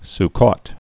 (s-kôt, s-kōs, skəs)